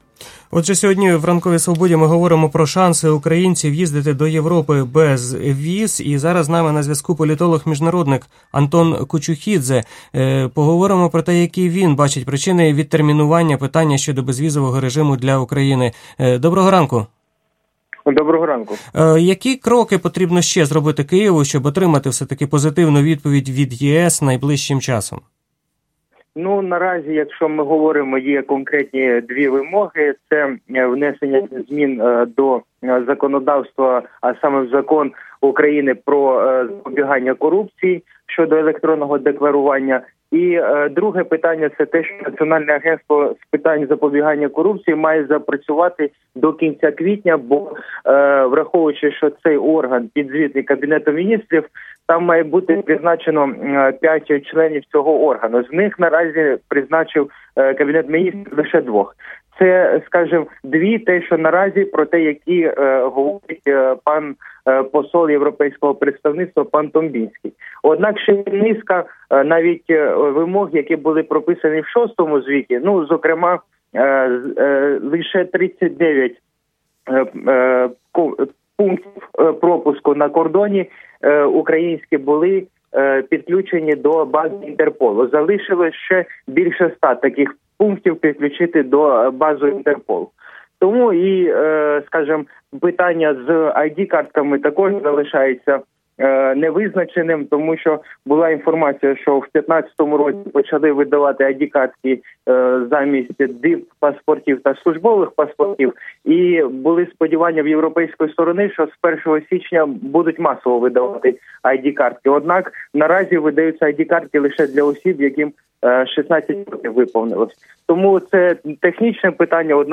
Про перспективи візової лібералізації йшлося в ефірі «Ранкової Свободи».